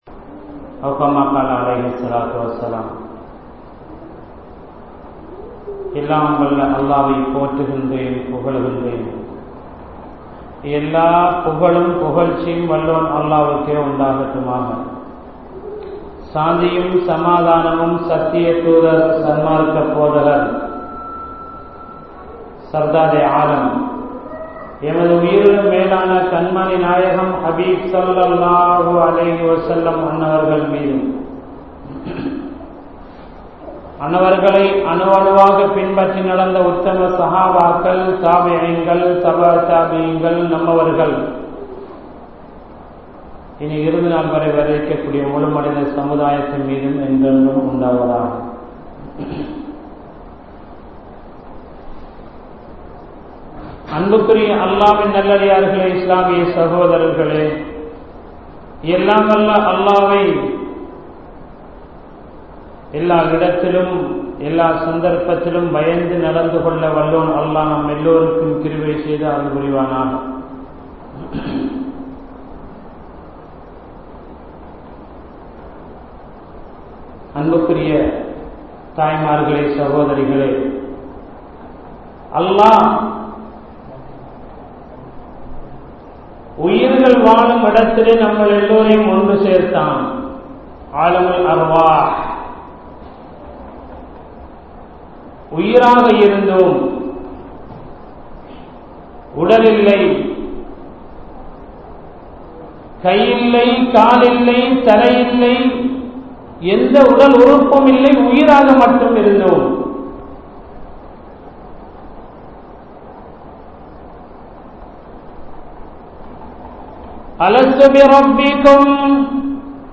Narahath`thai Noakkiya Pengal | Audio Bayans | All Ceylon Muslim Youth Community | Addalaichenai